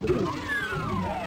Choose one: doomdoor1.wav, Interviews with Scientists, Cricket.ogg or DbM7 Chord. doomdoor1.wav